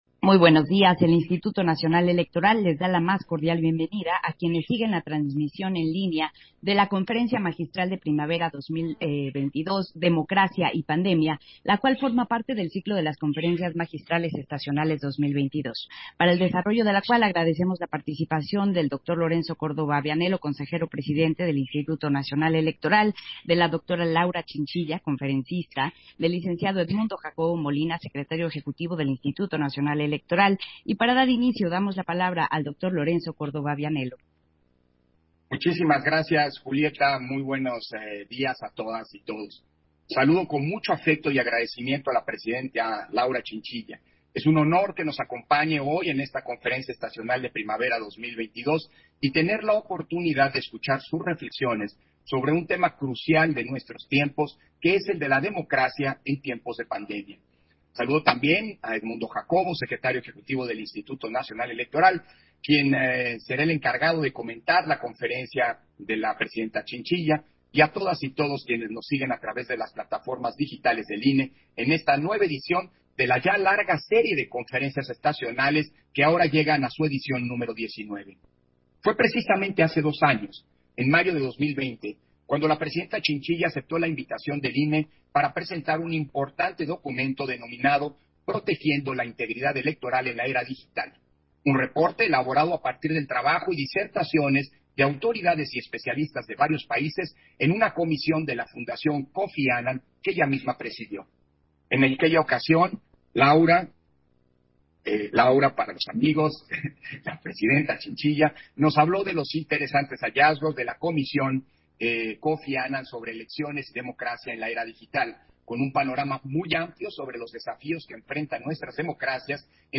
Versión estenográfica de la Conferencia Magistral Estacional de Primavera 2022, Democracia y Pandemia, impartida por la Dra. Laura chinchilla